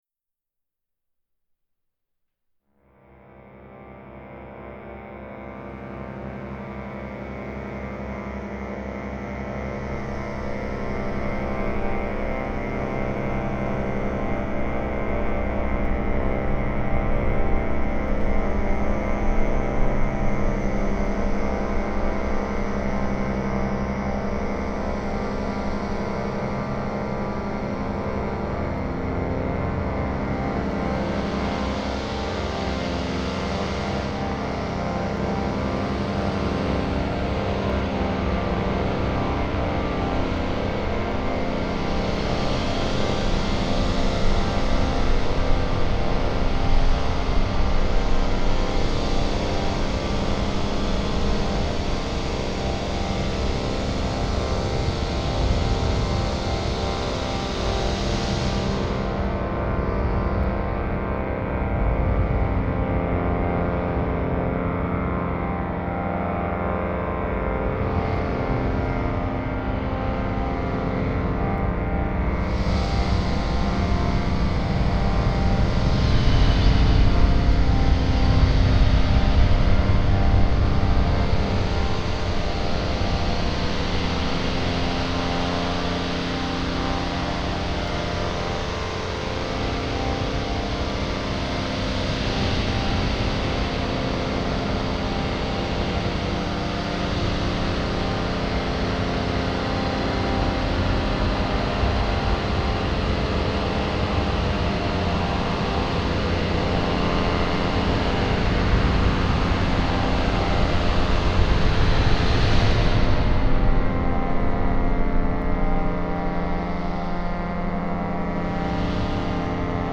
The Air Box_Ambiance
Framing and blowing a Magic Air Box.